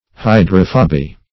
hydrophoby - definition of hydrophoby - synonyms, pronunciation, spelling from Free Dictionary
Hydrophoby \Hy"dro*pho`by\, n.